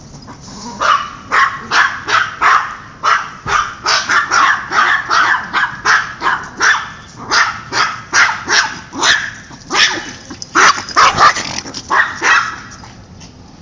Sprachmemo, Mobiltelefon, Samsung GT–C3590
+ einfache Bedienung, sehr gute Tonqualität, automatische Lautstärkenregelung
KanäleMono
Ich hatte ein paar kleine bellende Hunde aufgenommen und die Datei mit dem PC bearbeitet um nur die schönste Stelle von den Kläffern abzuspeichern. Die Qualität ist für eine Außenaufnahme meiner Meinung nach sehr gut.